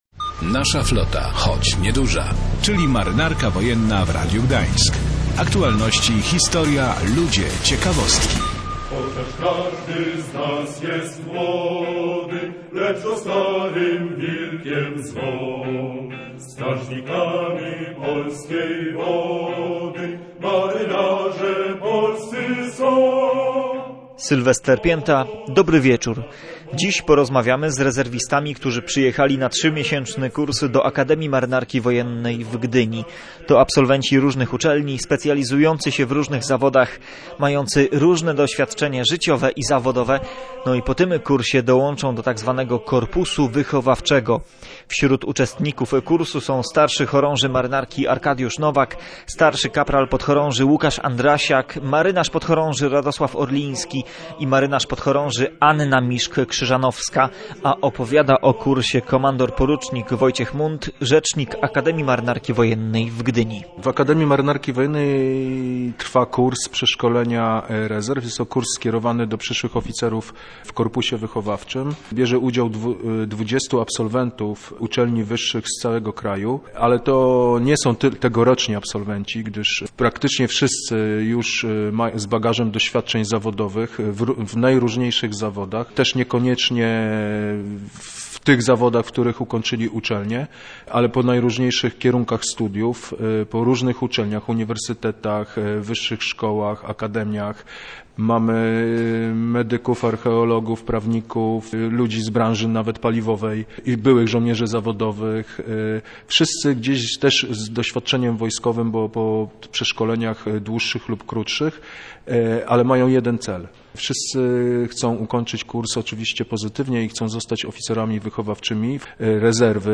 Dziś porozmawiamy z rezerwistami, którzy przyjechali na 3-miesięczny kurs do Akademii Marynarki Wojennej w Gdyni. To absolwenci różnych uczelni, specjalizujący się w różnych zawodach, mający różne doświadczenie życiowe i zawodowe.